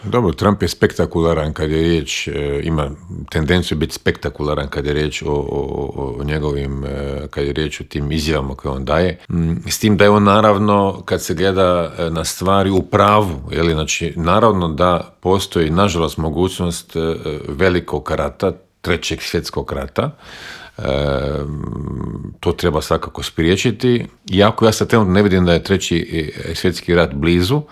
ZAGREB - Bivši ministar vanjskih poslova Miro Kovač gostovao je u Intervjuu Media servisa u kojem se, među ostalim, osvrnuo na rezolucije o Ukrajini koju je Opća skupština Ujedinjenih naroda jučer donijela povodom treće obljetnice rata u toj zemlji.